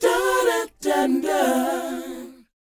DOWOP G FD.wav